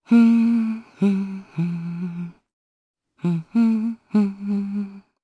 Hilda-Vox_Hum_jp.wav